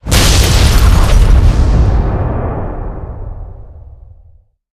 mukeExplosion.ogg